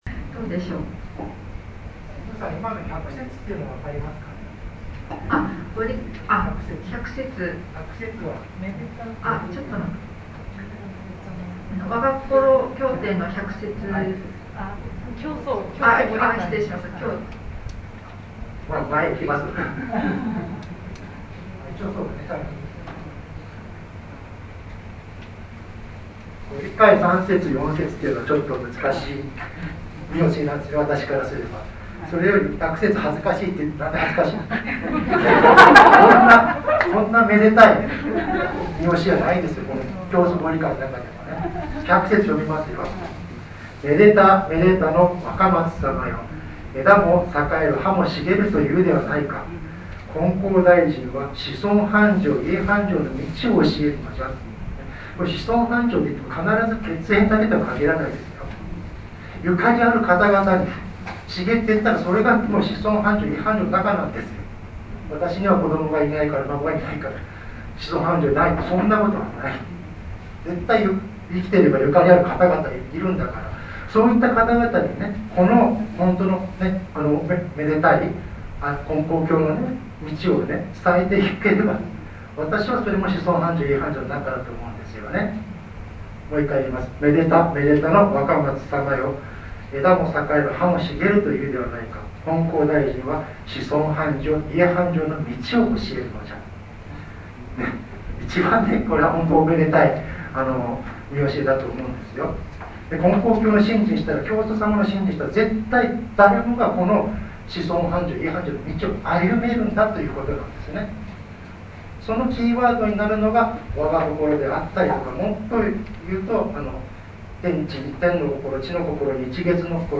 生神金光大神大祭･祭主挨拶